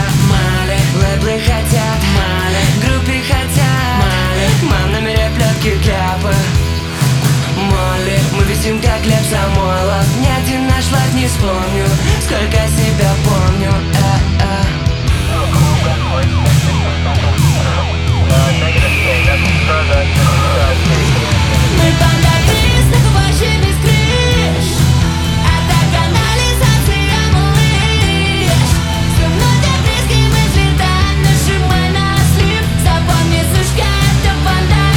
Жанр: Русская поп-музыка / Поп / Рок / Русский рок / Русские